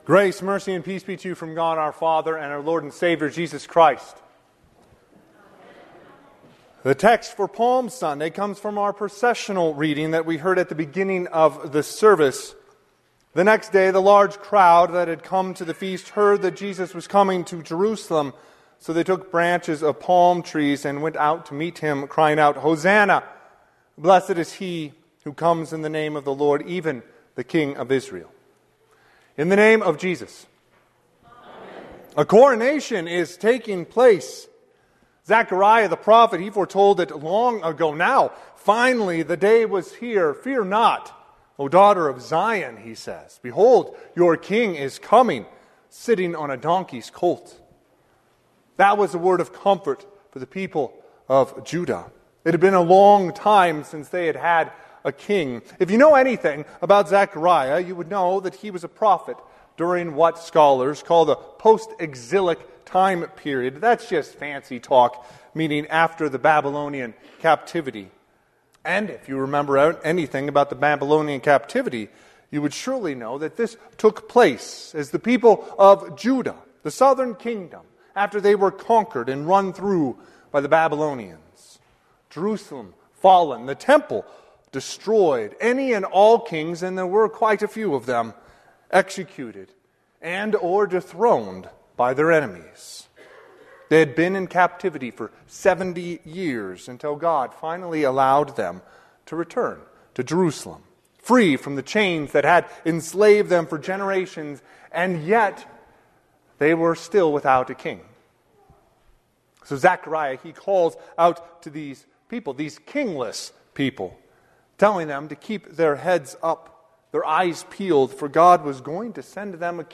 Sermon - 4/13/2025 - Wheat Ridge Lutheran Church, Wheat Ridge, Colorado
Palm Sunday